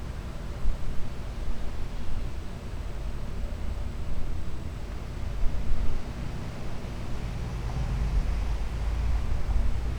urban